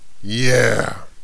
warrior_ack5.wav